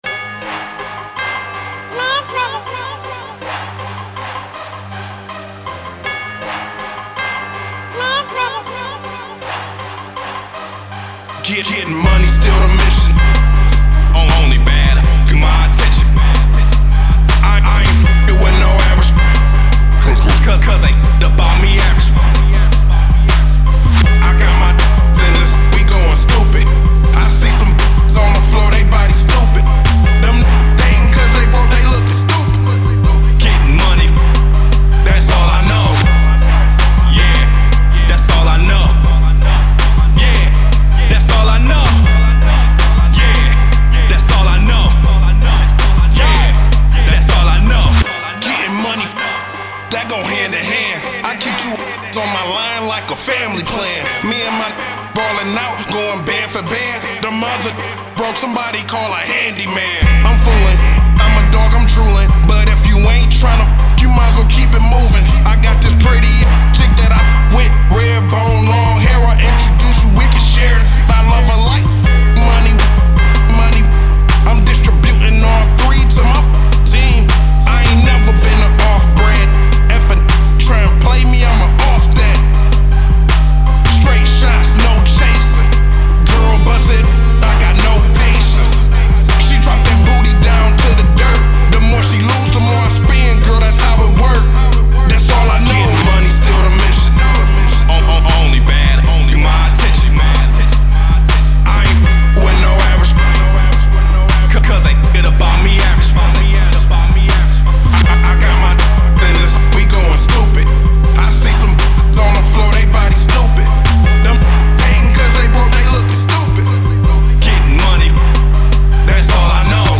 Another club BANGER